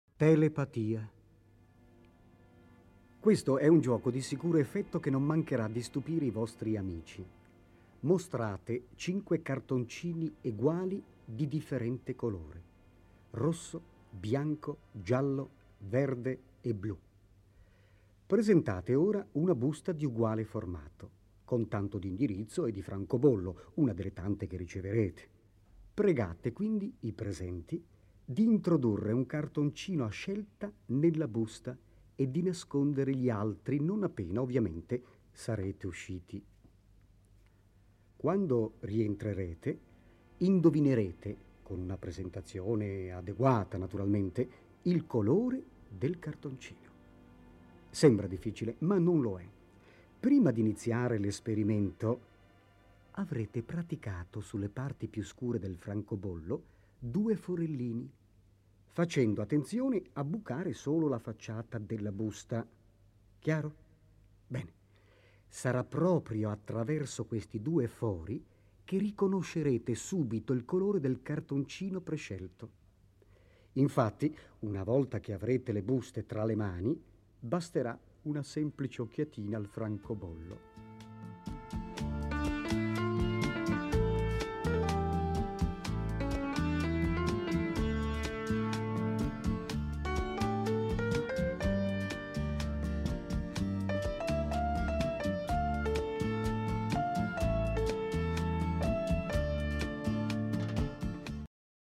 Le ultime tre tracce erano altrettanti giochi di prestigio spiegati da Silvan su un sottofondo musicale.